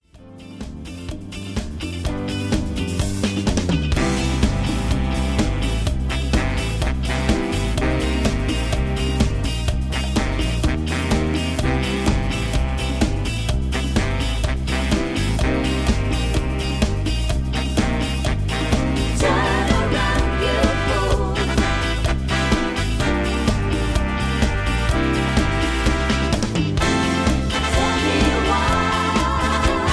backing tracks